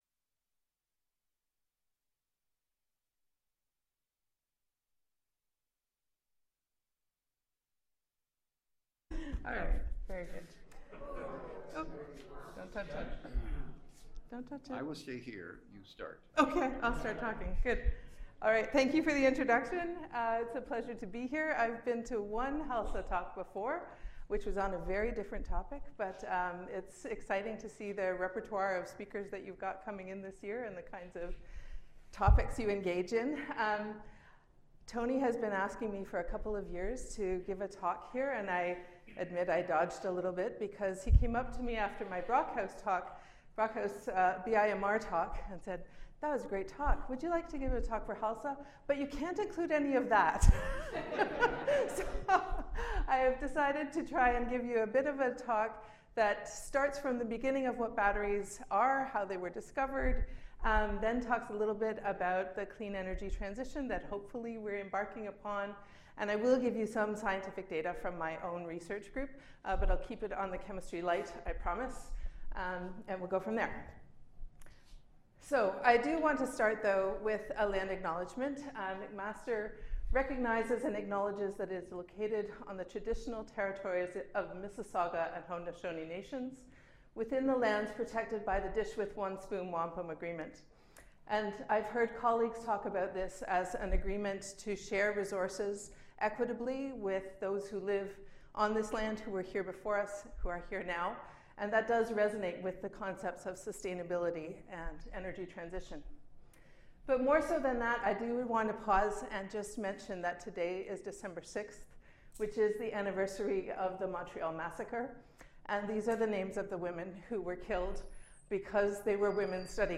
The first 9 seconds have no sound.